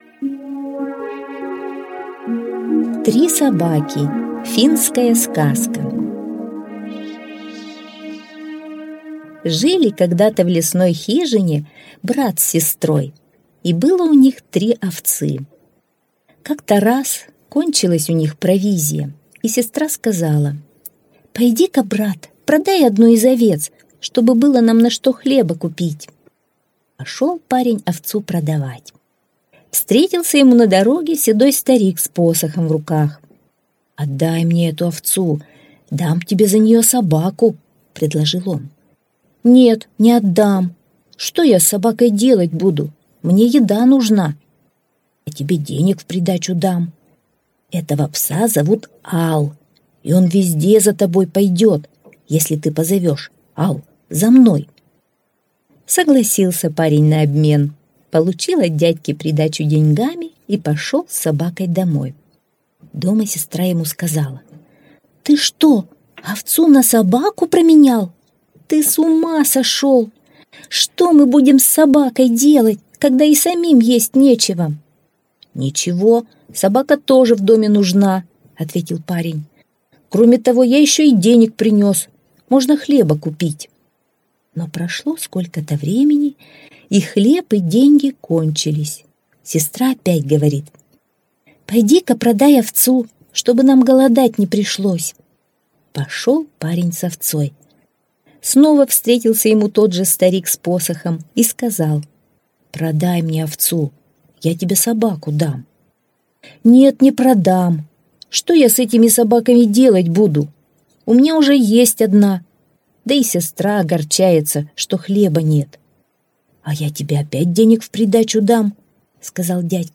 Три собаки - финская аудиосказка - слушать онлайн